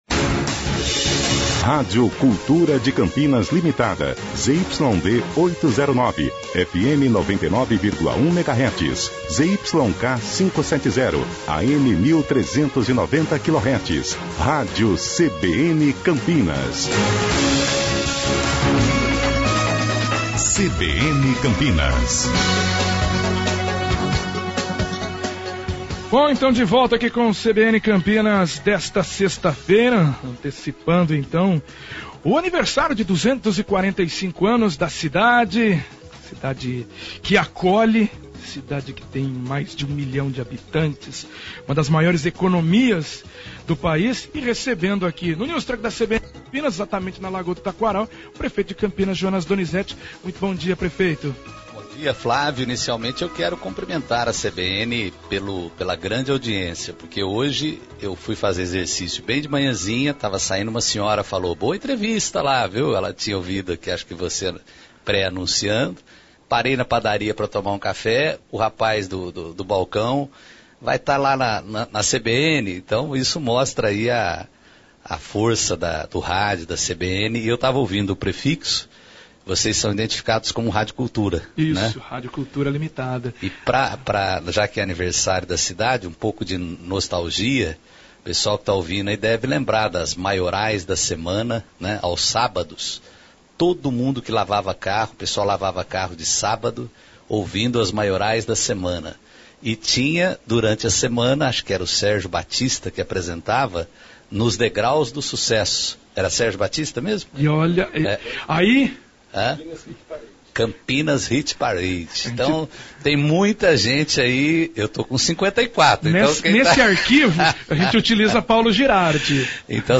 Prefeito de Campinas, Jonas Donizette fala no News Truck dos 245 Anos da Cidade - CBN Campinas 99,1 FM